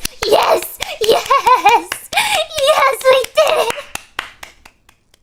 Worms speechbanks
Victory.wav